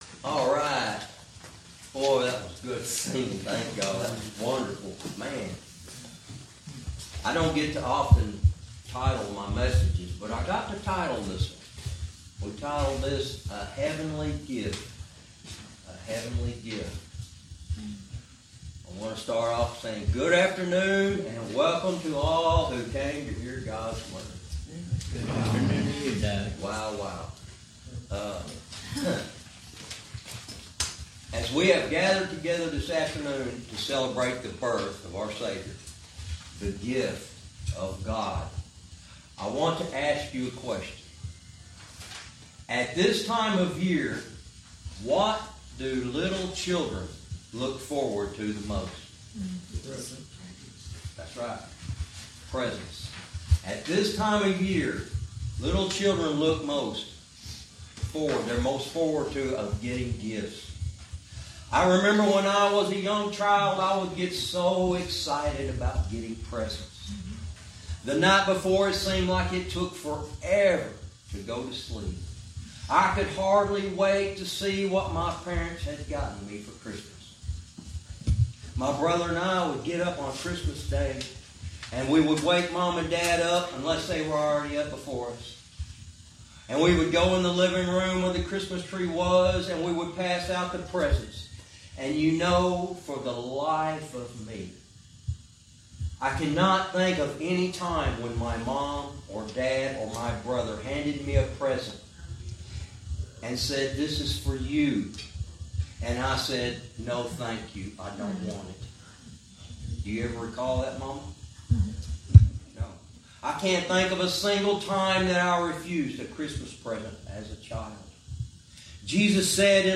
Verse by verse teaching - Christmas 2025 (A HEAVENLY Gift)